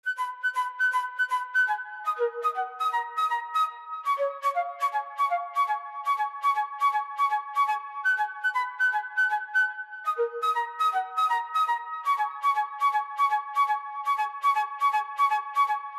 标签： 120 bpm Hip Hop Loops Flute Loops 1.35 MB wav Key : Unknown
声道立体声